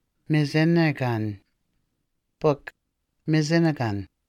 Anishinaabemowin Odawa: Mizinigan    [Mi zi ni gan]